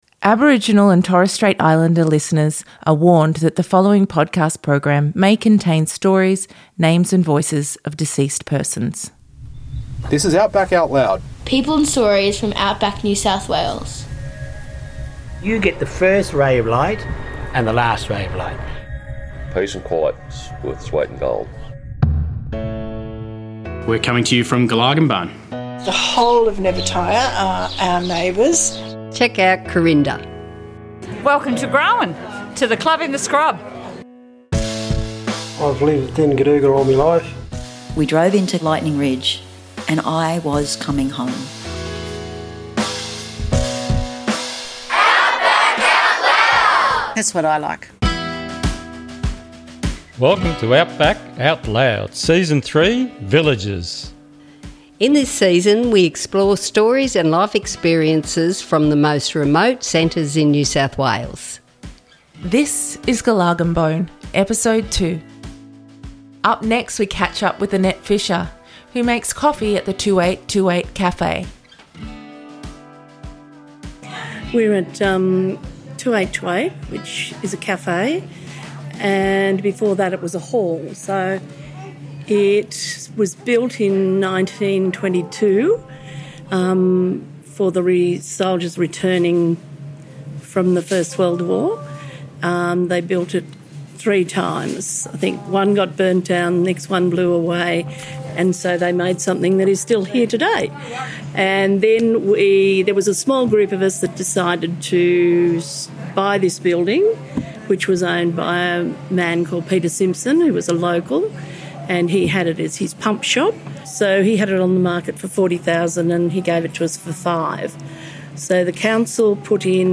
In this Episode we hear interviews from people in Gulargambone NSW, Wailwan and Gamilaroi Country. This episode features stories, memories and reflections about Gular- as it is known by the locals.